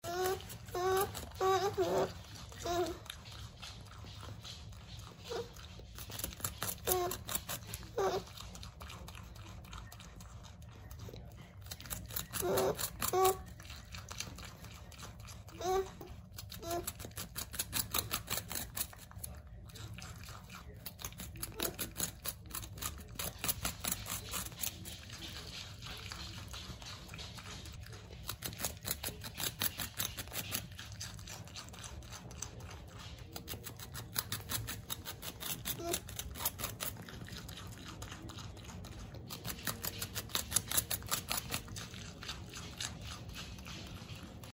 Звуки бобров